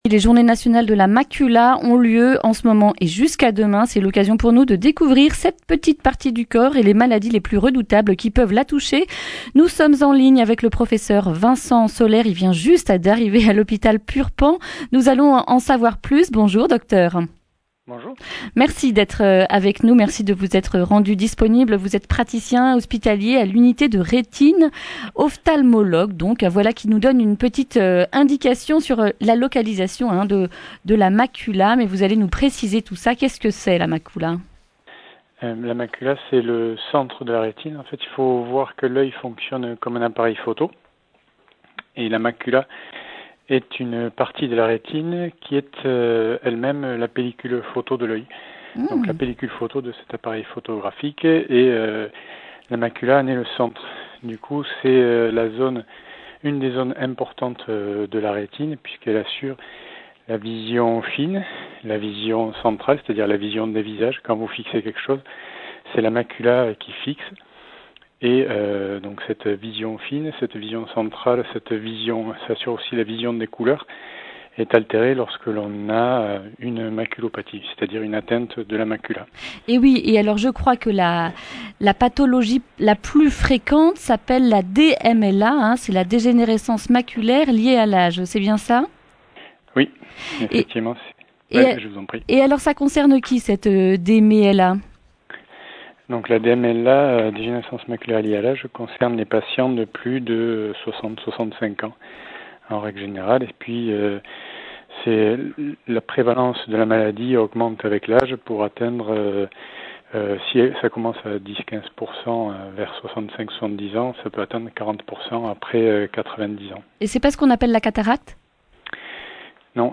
jeudi 27 juin 2019 Le grand entretien Durée 11 min